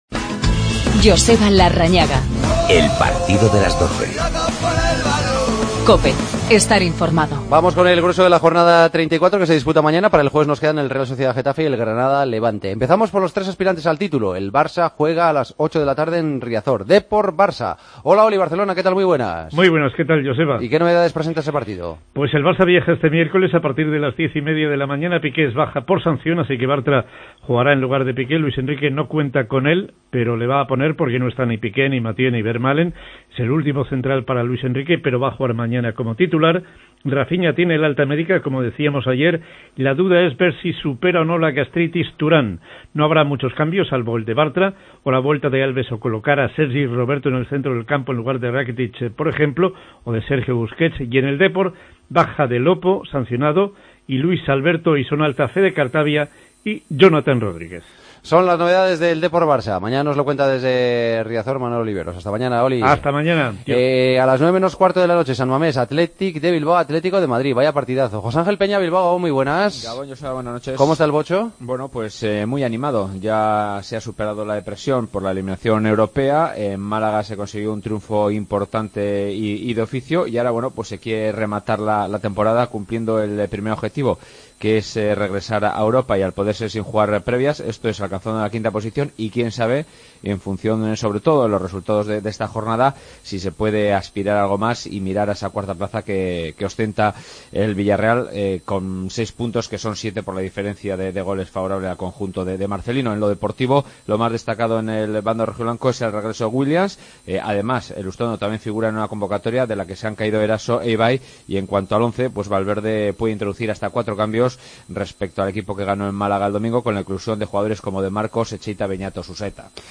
Previa de la 34ª jornada de Liga, con especial atención a los partidos de Barcelona, Atlético y Real Madrid ante Deportivo, Athletic y Villarreal. Entrevista a Raúl García. Espanyol y Celta empataron 1-1 y el Betis seguirá en Primera tras ganar 1-0 a Las Palmas.